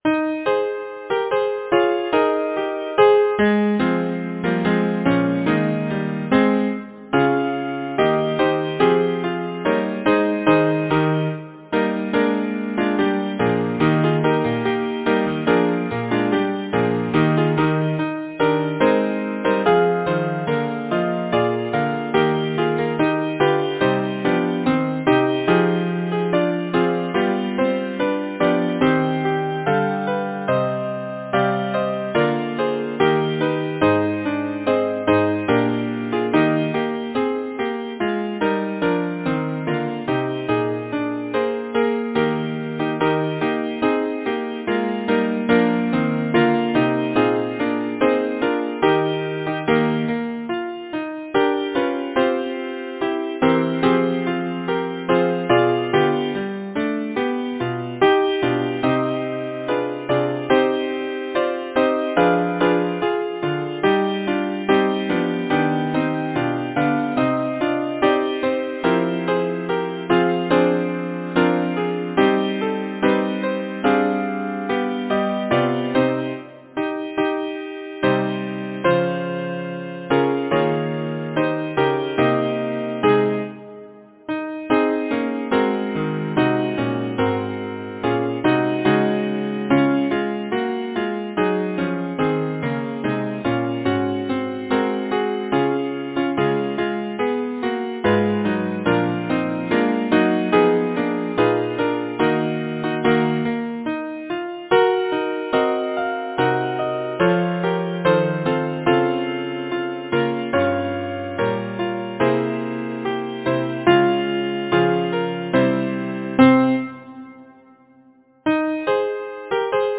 Title: Before me, careless lying Composer: Charles Harford Lloyd Lyricist: Henry Austin Dobson Number of voices: 5vv Voicing: SSATB, divisi Genre: Secular, Partsong
Language: English Instruments: A cappella